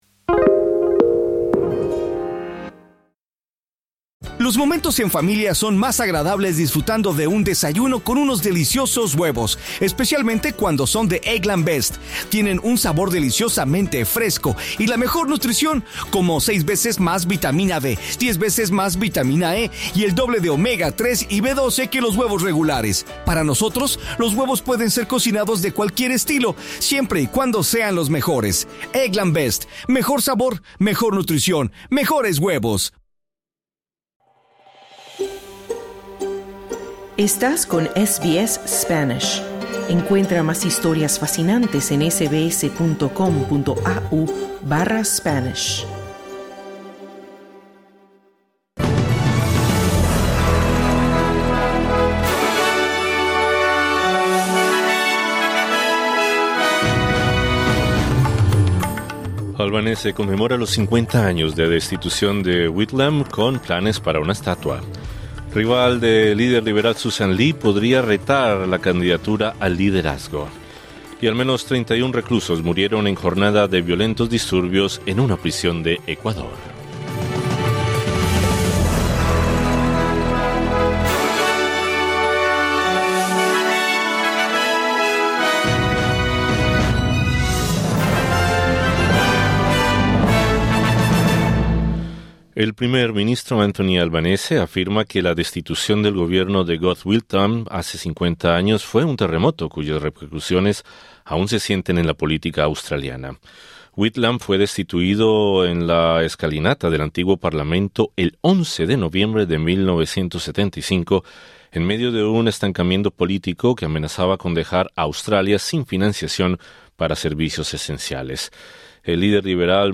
Anthony Albanese afirma que la destitución del gobierno de Gough Whitlam, hace 50 años, fue un terremoto cuyas repercusiones aún se sienten en la política australiana. Escucha el resumen de noticias de este martes 11 de noviembre 2025.